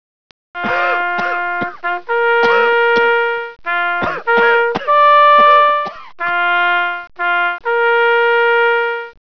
A weird version of taps that I found somewhere.
taps.wav